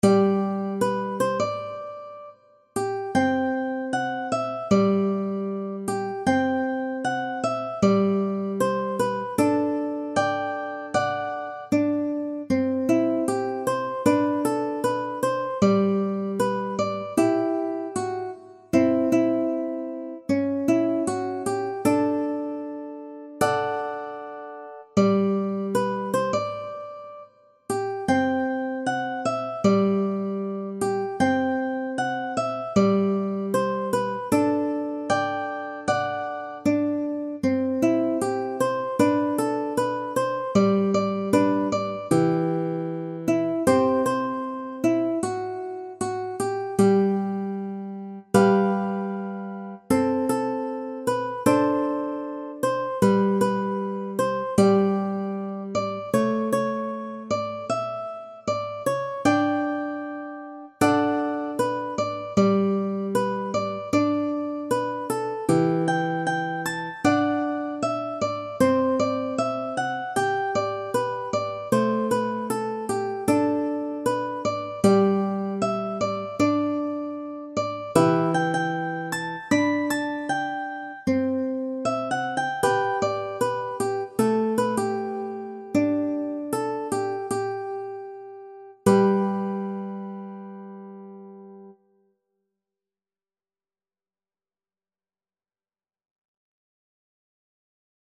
J-POP / ポップス / 男性ソロ
アップした楽譜は原曲の特徴や魅力を損なわず、ギター初級の方向けにギターソロで弾けるようにアレンジしました。
PC演奏（楽譜をそのままMP3にエクスポート）ですが